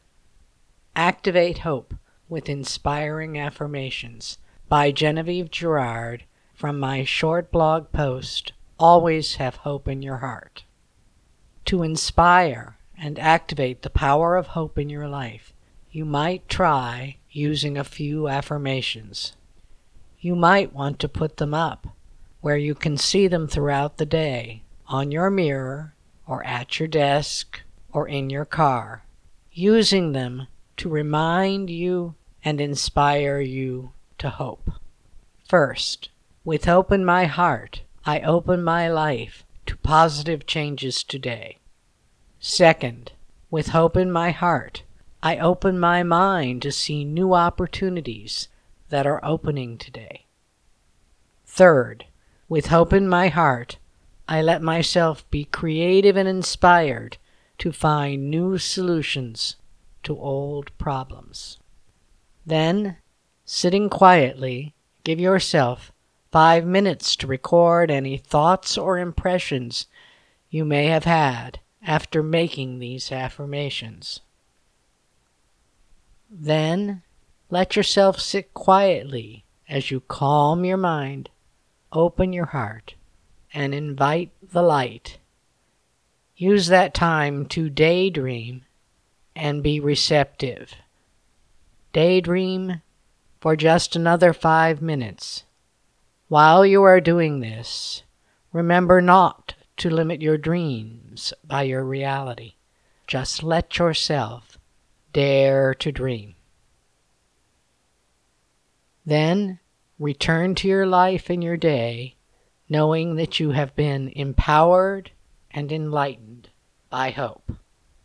Click play icon below to listen to me reading these affirmations to you (under 2 minutes).